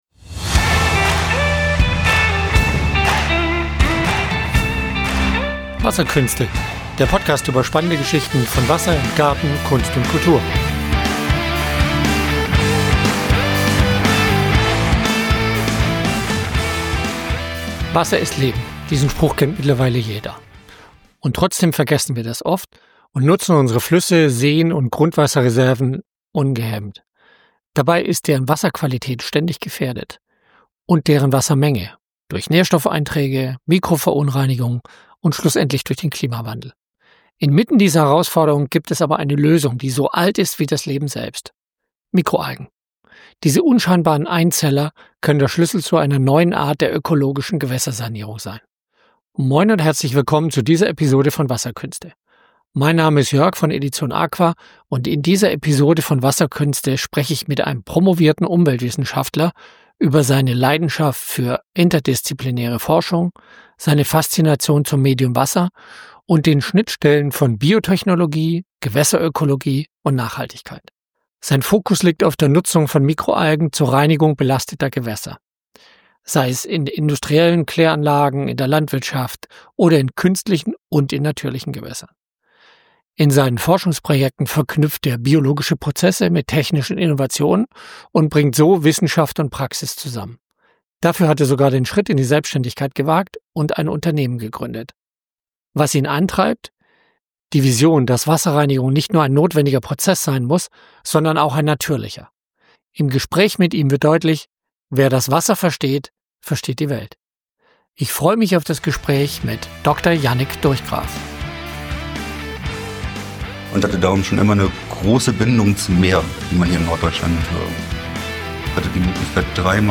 Ein wesentlicher Teil unseres Gesprächs dreht sich um die praktischen Aspekte der Wasserreinigung mit Mikroalgen und die Integration dieser Technologie in bestehende Systeme.